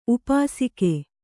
♪ upāsike